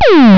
Peeeooop X
peeeooop_x.wav